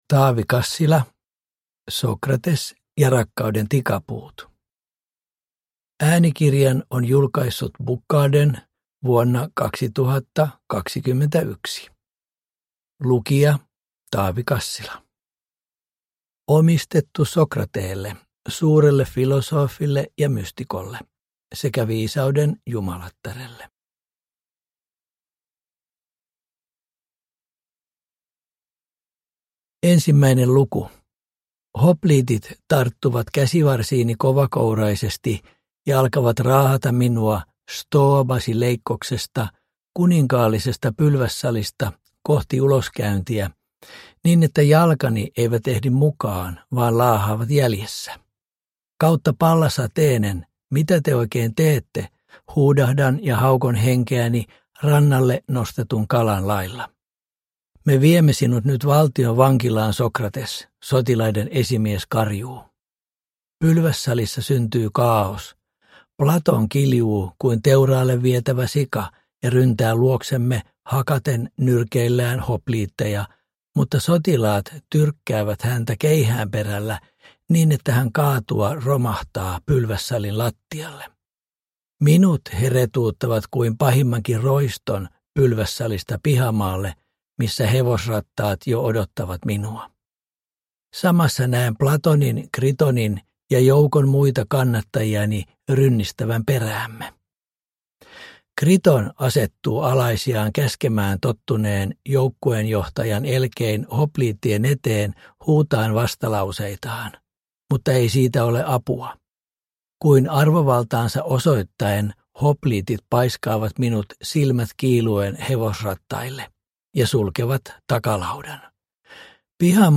Sokrates ja rakkauden tikapuut – Ljudbok – Laddas ner